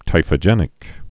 (tīfə-jĕnĭk)